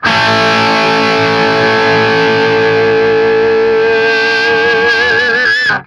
TRIAD A   -R.wav